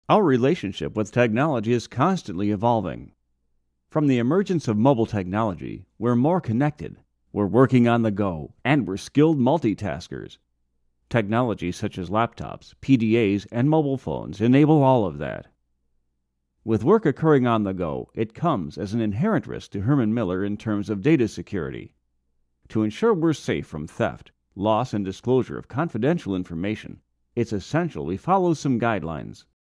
U.S. English ,Male, Middle Age, Other qualities include Authoritative, Believable, Confident, Conversational, Corporate, Educated, Genuine,Informative, Intelligent, Mature, Narrator
middle west
Sprechprobe: Industrie (Muttersprache):